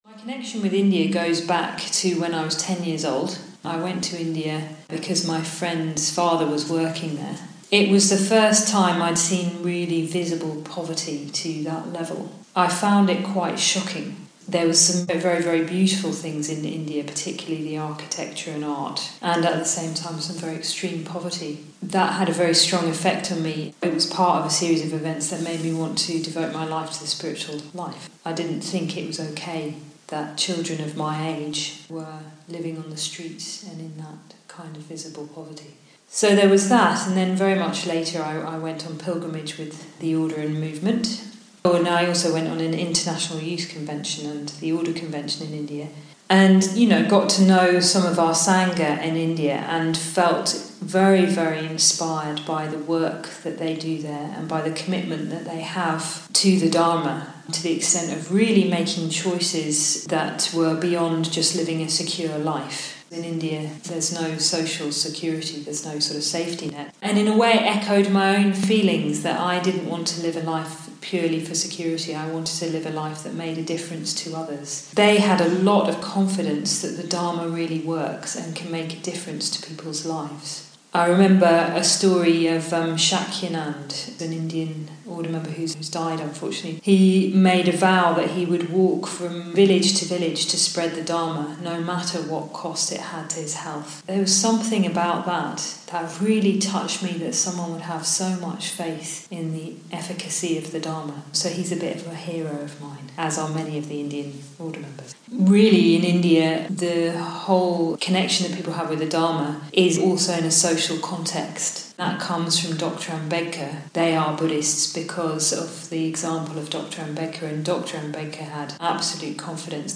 Recorded at Adhisthana, 1 December 2018.